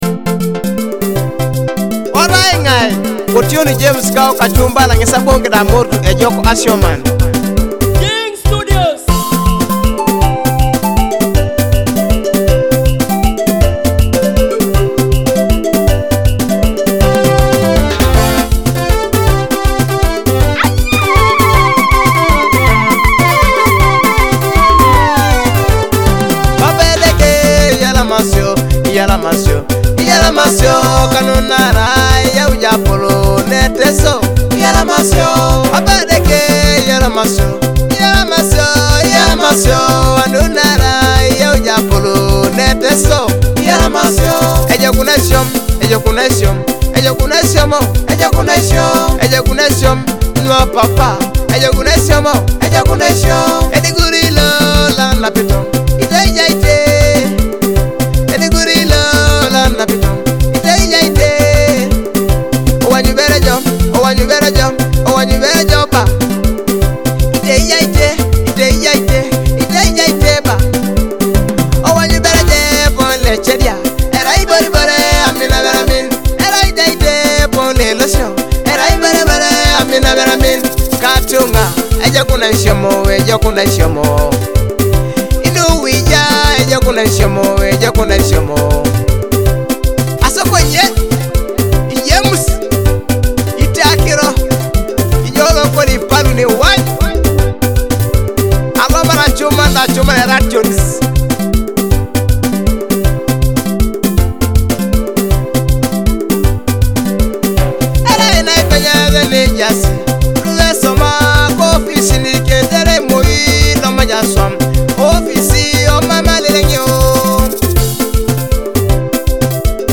Discover Teso music with Akogo